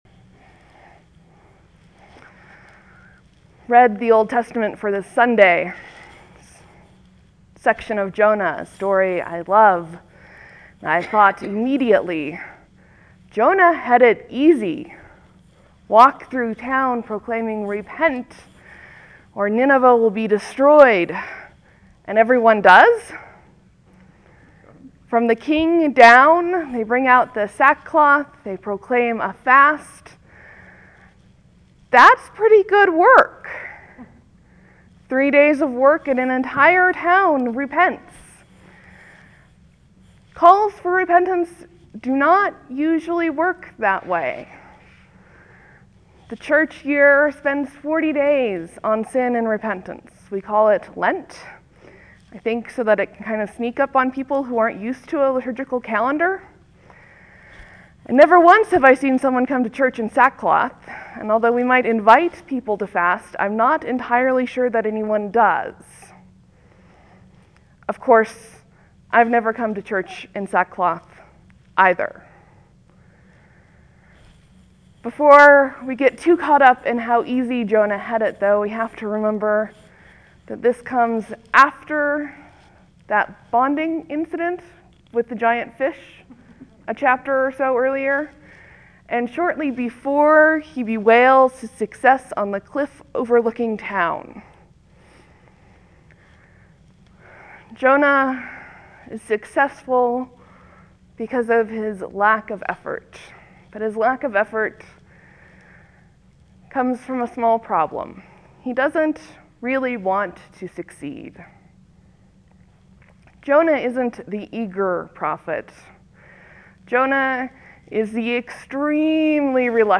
(There will be a few moments of silence before the sermon starts.